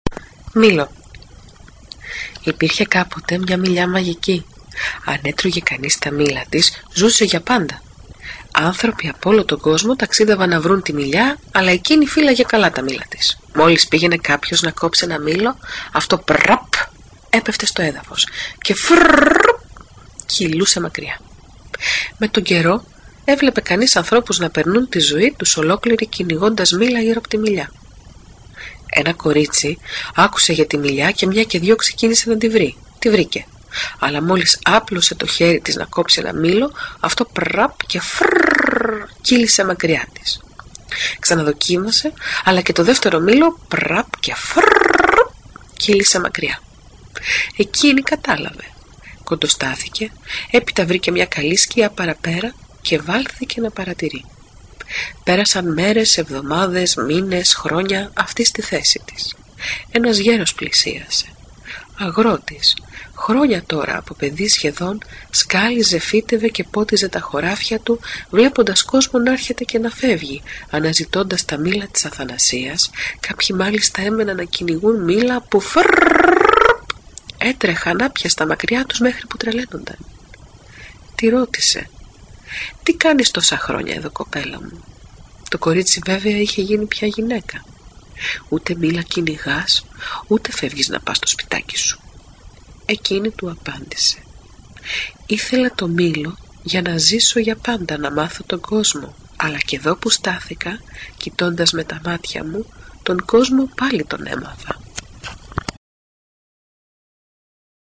αφήγηση
sxoleio thalassa tsanda λεξο ιστορίες